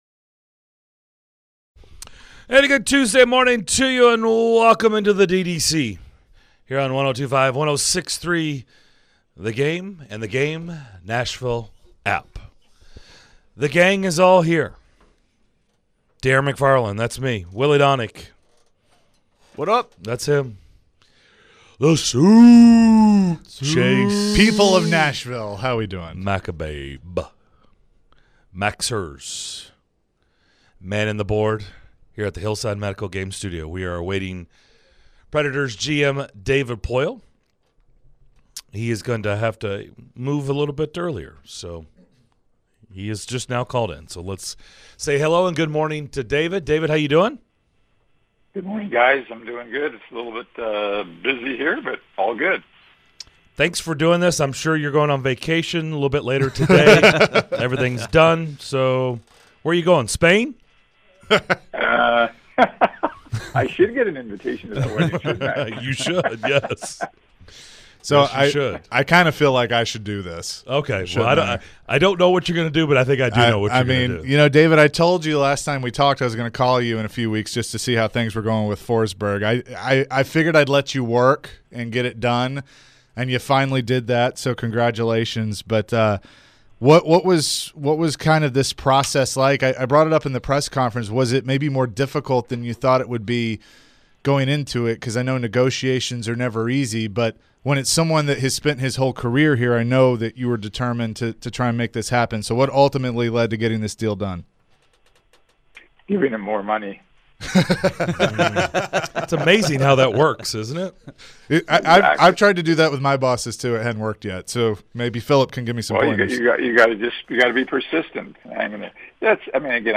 David Poile Full Interview (07-12-22)